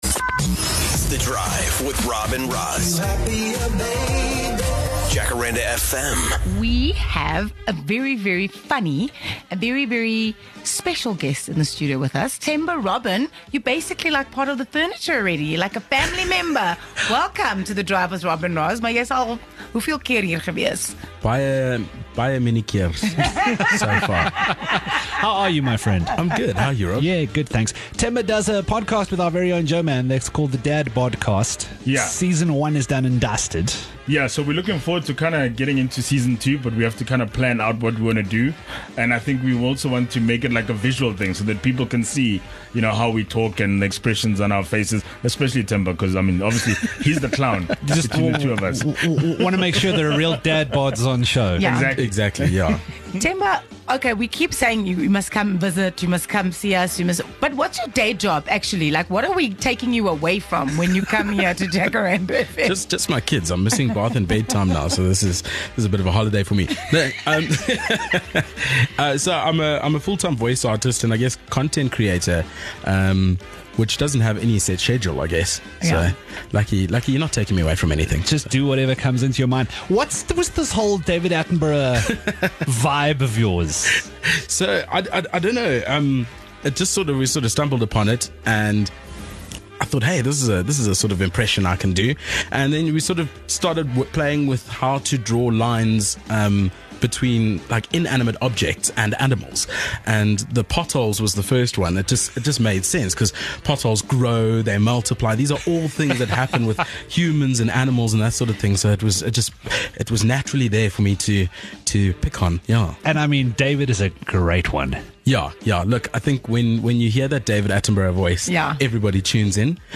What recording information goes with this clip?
This might be the funniest video we've seen so you know we had to get him into the studio to talk about the inspiration behind his mockumentary.